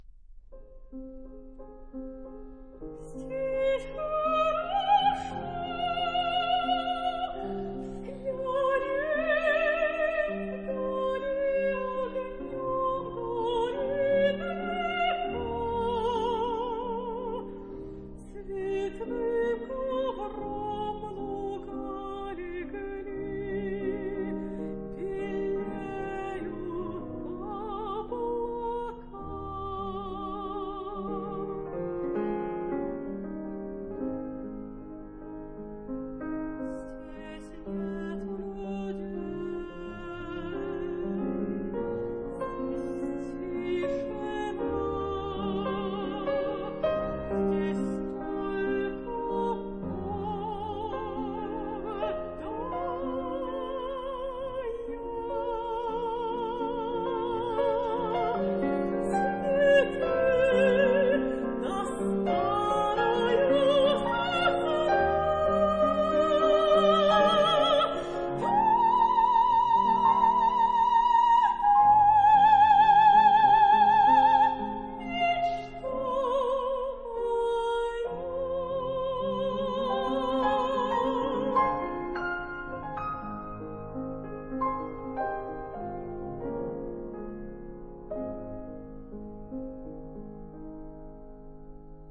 這位被《紐約時報》形容聲音「很野」的女高音，
鋼琴版雖然只有一架鋼琴，但張力一點都不輸。